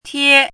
chinese-voice - 汉字语音库
tie1.mp3